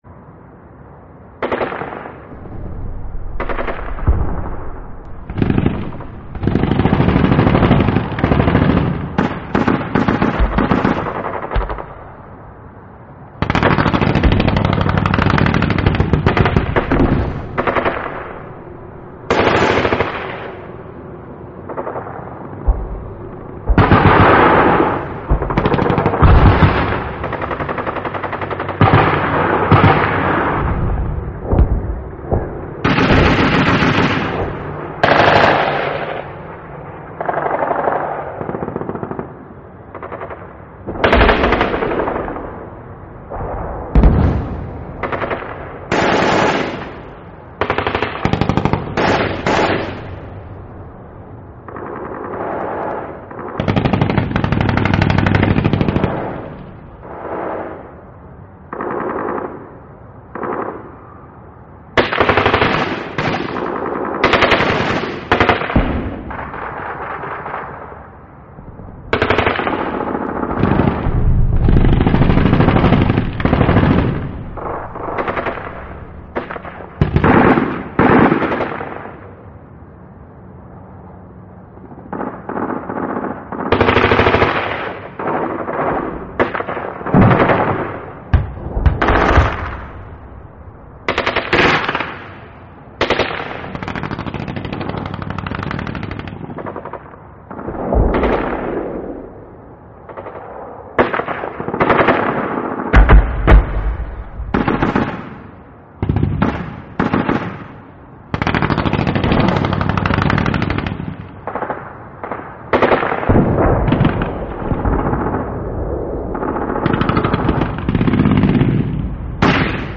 На этой странице собраны звуки войны и перестрелок — тревожные, резкие, напоминающие о разрушениях.
Военные выстрелы и шумы поля боя